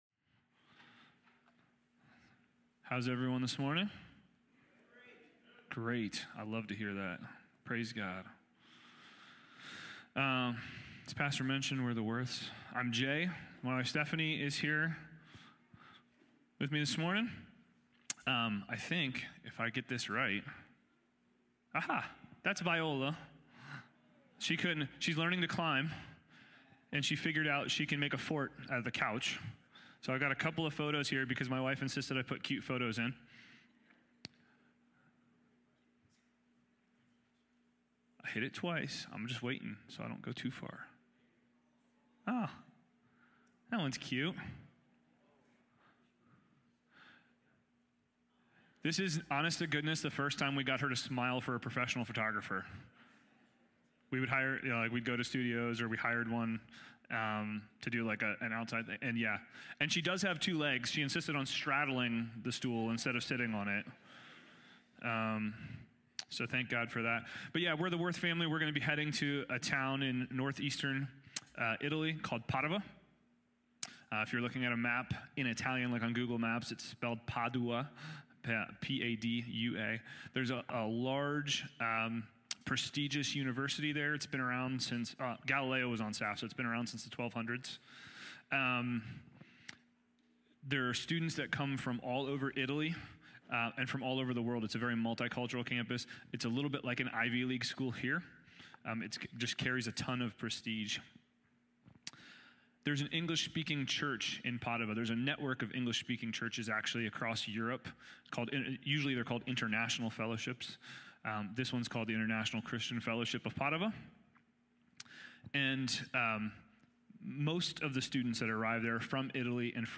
Sunday morning, livestreamed from Wormleysburg, PA.